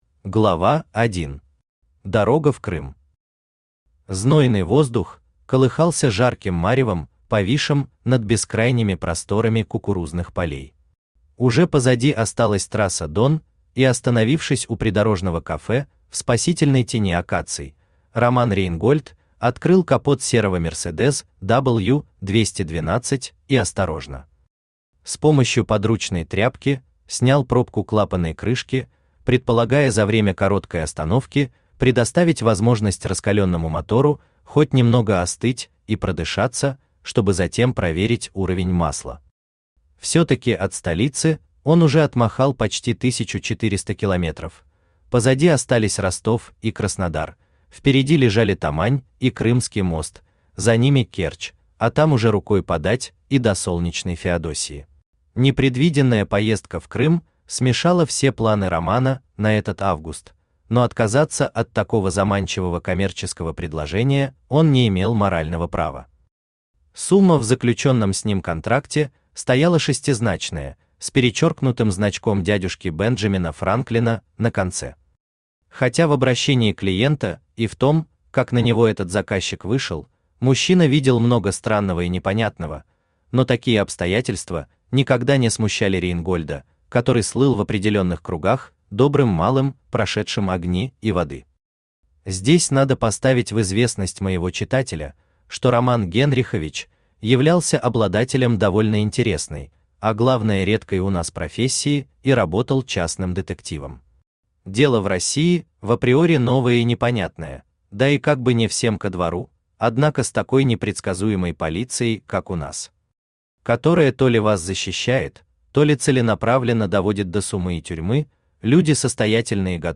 Аудиокнига Золото скифов (Крымский карамболь) | Библиотека аудиокниг
Aудиокнига Золото скифов (Крымский карамболь) Автор Андрей Воронин Читает аудиокнигу Авточтец ЛитРес.